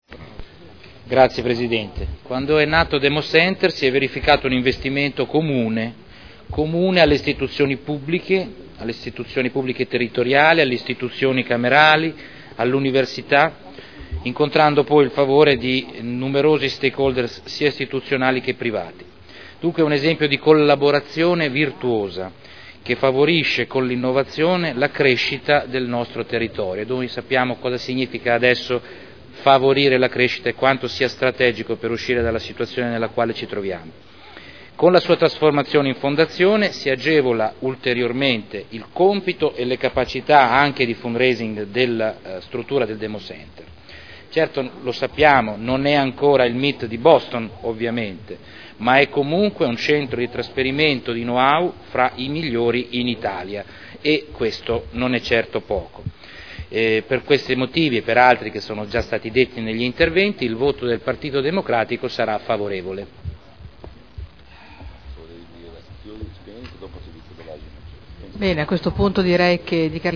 Salvatore Cotrino — Sito Audio Consiglio Comunale
Dichiarazione di voto su proposta di deliberazione. Trasformazione di Democenter-Sipe da Società Consortile a Responsabilità Limitata a Fondazione – Approvazione dello statuto